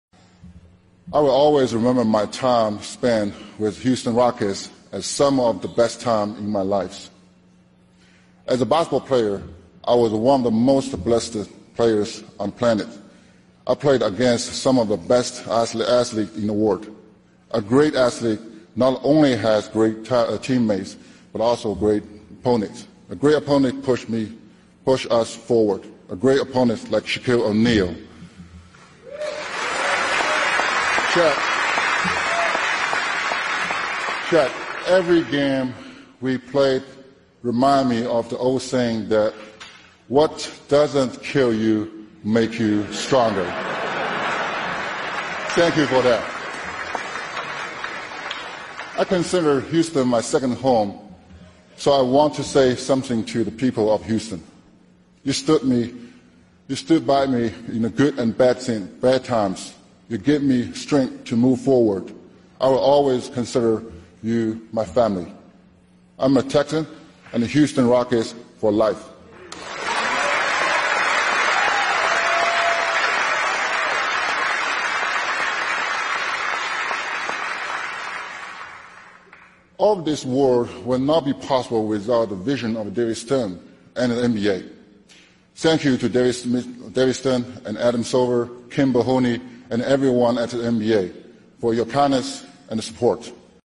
篮球英文堂 第15期:姚明名人堂演讲 听力文件下载—在线英语听力室